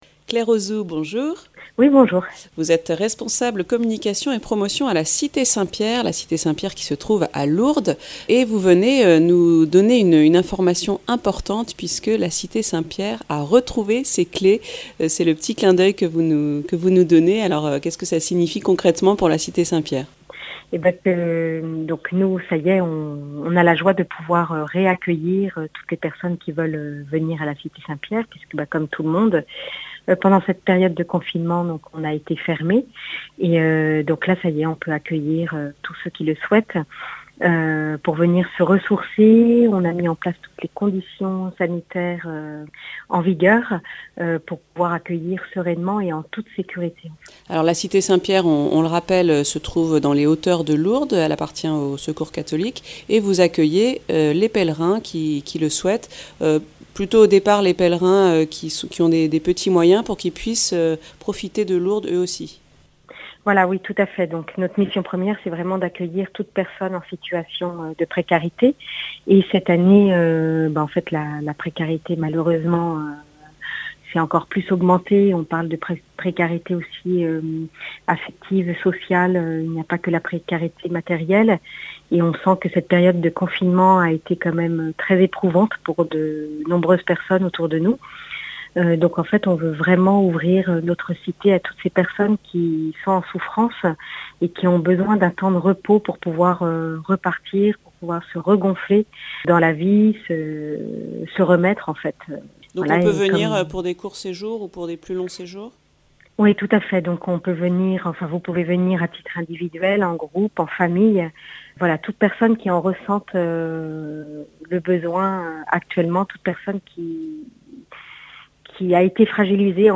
08H00 | Interviews et reportages